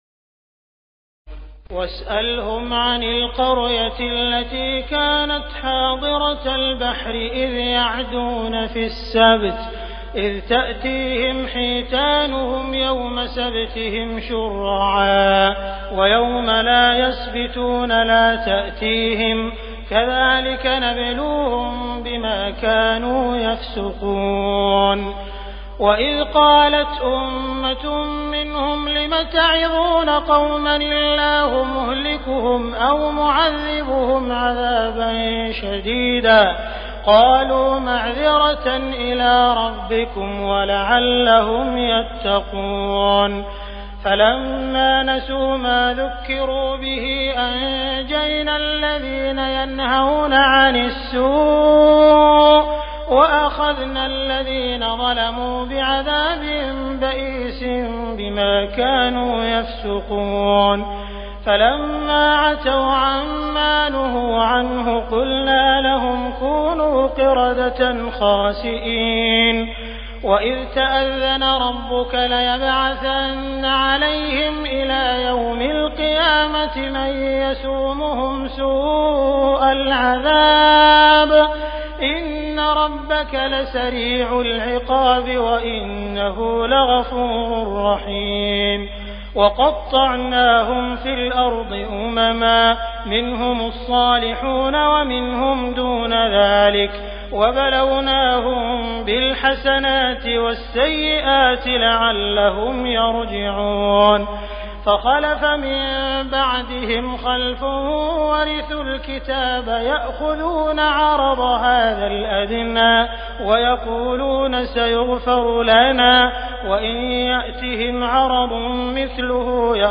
تراويح الليلة التاسعة رمضان 1418هـ من سورتي الأعراف (163-206) والأنفال (1-40) Taraweeh 9 st night Ramadan 1418H from Surah Al-A’raf and Al-Anfal > تراويح الحرم المكي عام 1418 🕋 > التراويح - تلاوات الحرمين